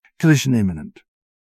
jankboard/client/public/static/voices/en-UK/collision-imminent.wav at refactor-rust
collision-imminent.wav